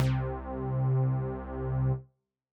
IBI Bass C1.wav